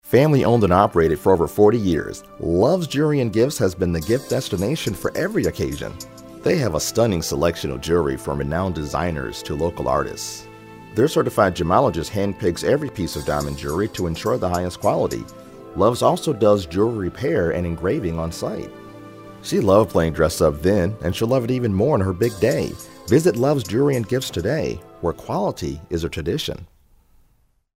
Company Advertisement
Original music written and produced